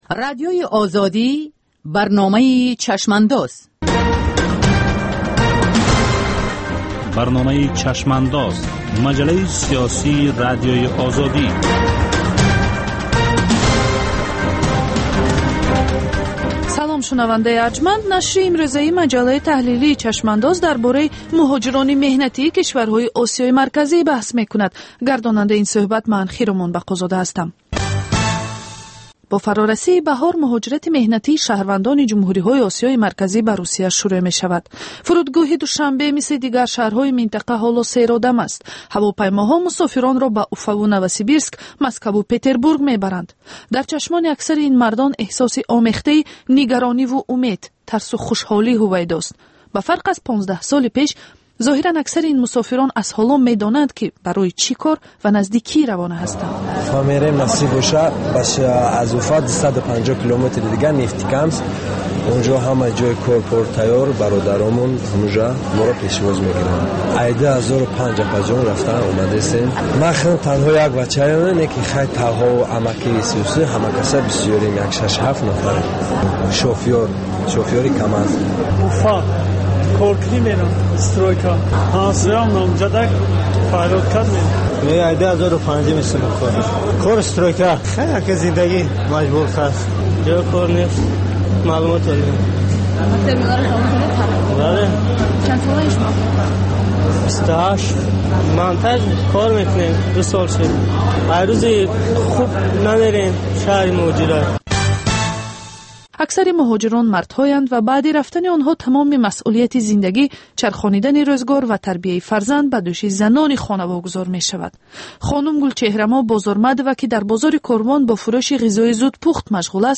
Сӯҳбати ошкоро бо чеҳраҳои саршинос ва мӯътабари Тоҷикистон дар мавзӯъҳои гуногун, аз ҷумла зиндагии хусусӣ.